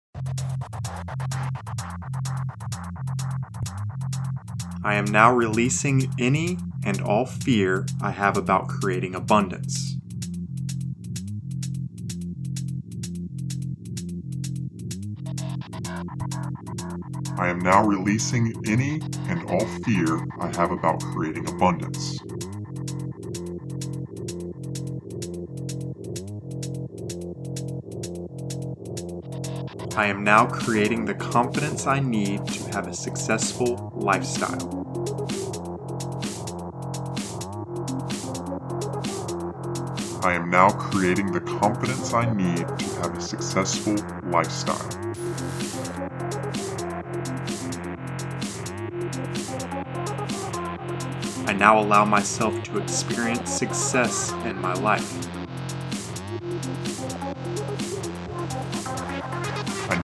This workout affirmation track for success & abundance was created per request. It is very fast-paced and TOTALLY different than anything I’ve published before.